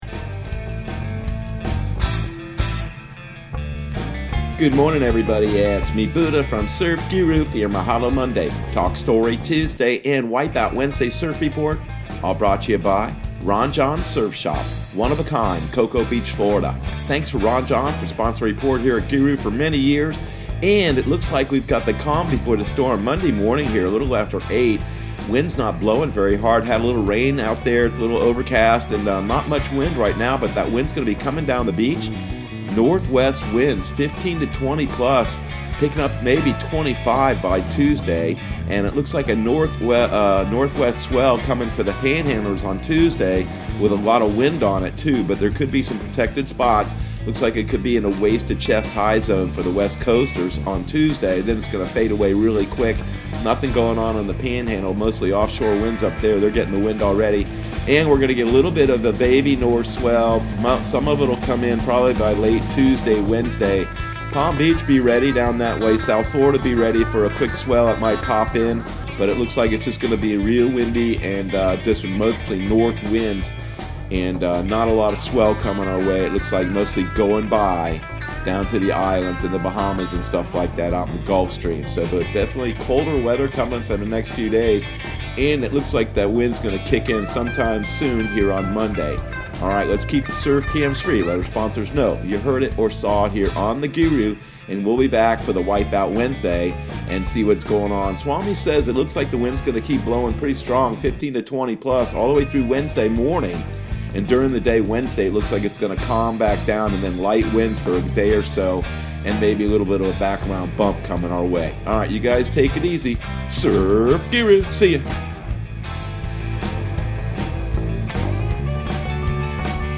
Surf Guru Surf Report and Forecast 12/07/2020 Audio surf report and surf forecast on December 07 for Central Florida and the Southeast.